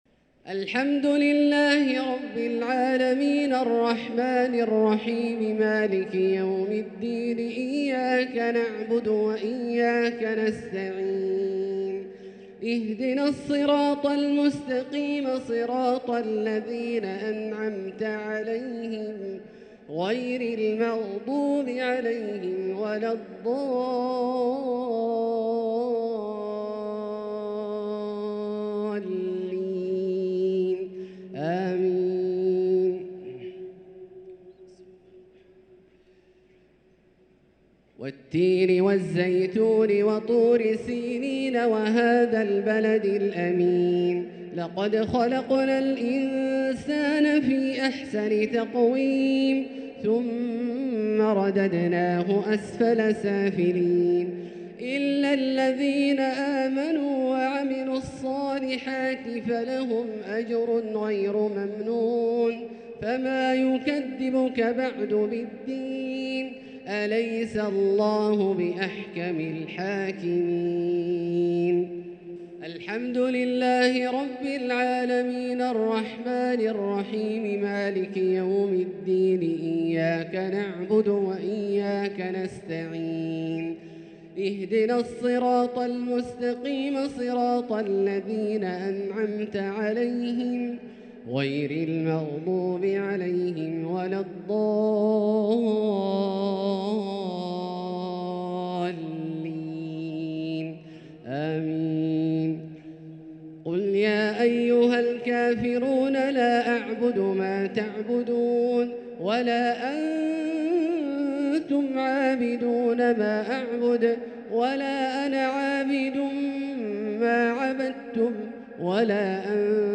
الشفع و الوتر ليلة 7 رمضان 1444هـ | Witr 7 st night Ramadan 1444H > تراويح الحرم المكي عام 1444 🕋 > التراويح - تلاوات الحرمين